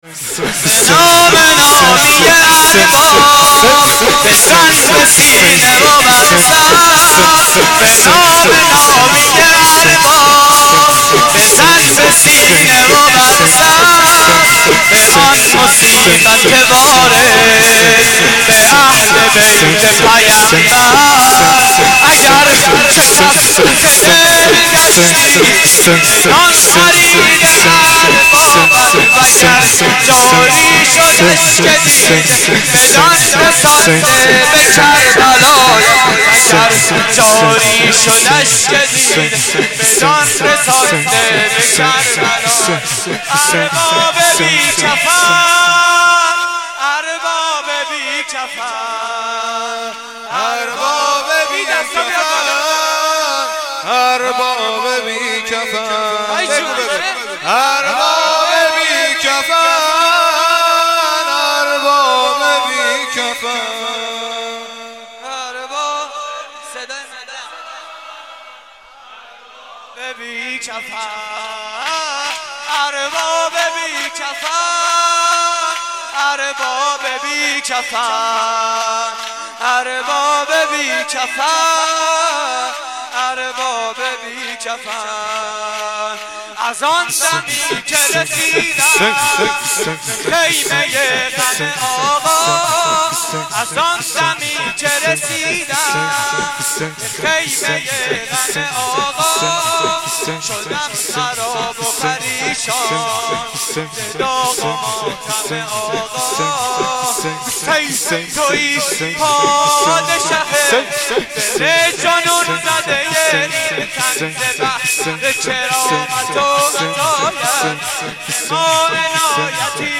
مراسم عزاداری ماه محرم
صوت مراسم:
شور: به نام نامی ارباب؛ پخش آنلاین |